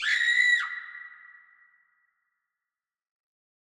[ACD] - 9PieceScream.wav